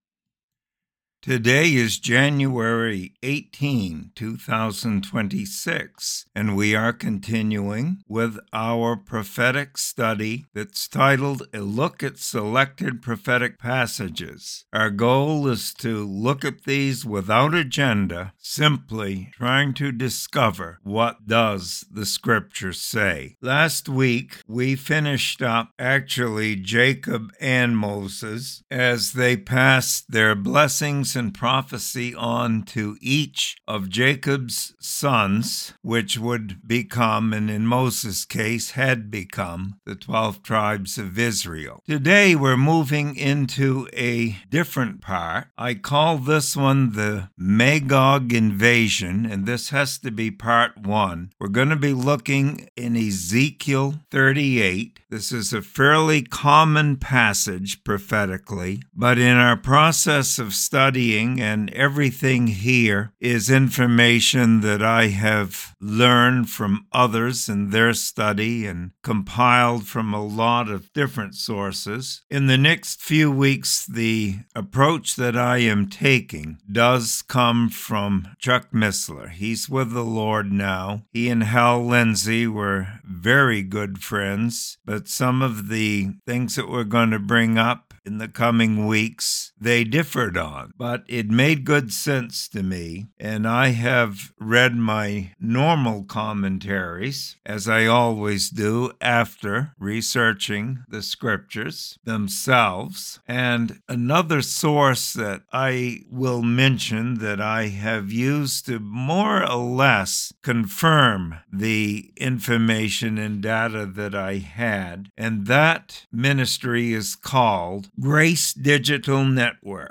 Sermon Library | First Baptist Church of Troy NH